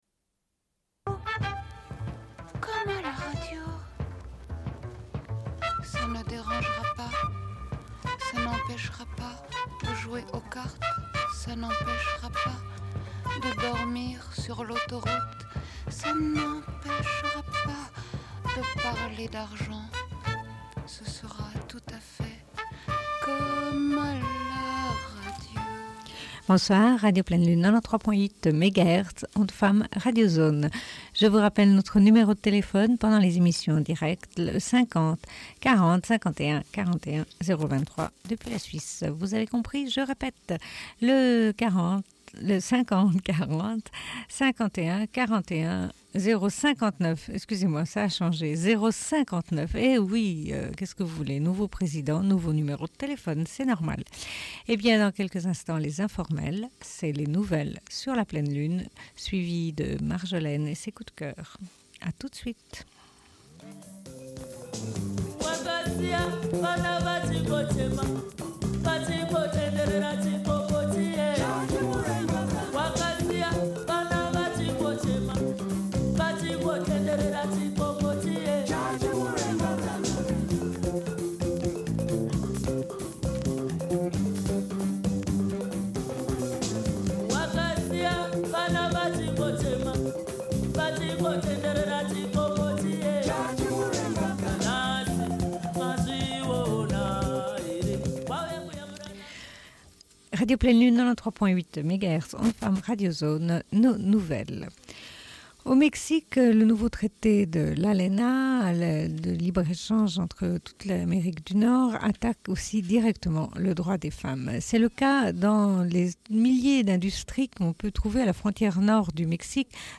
Une cassette audio, face B30:11